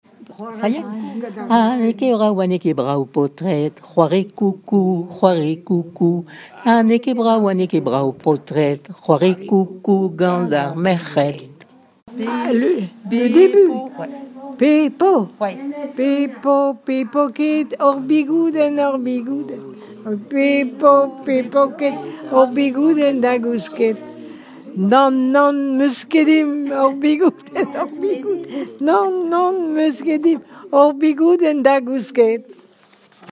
Cantiques et témoignages en breton
Pièce musicale inédite